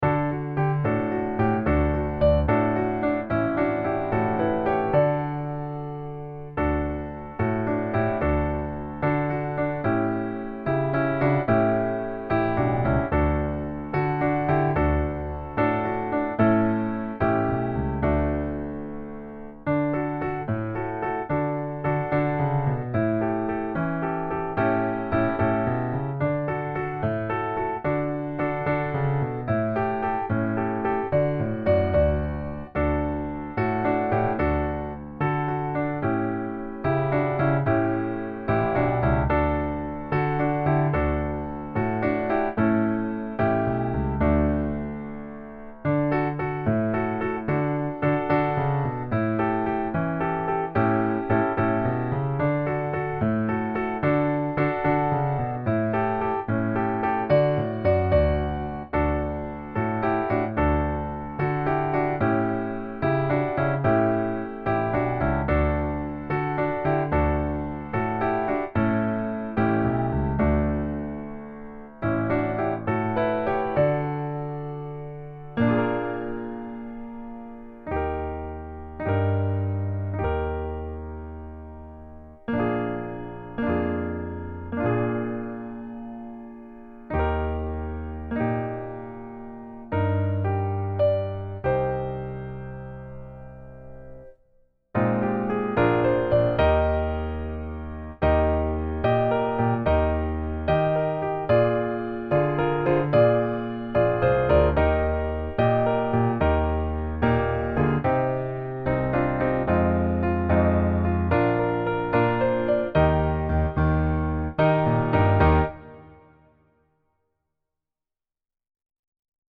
Composer: English Folk Song
Voicing: SSA and Piano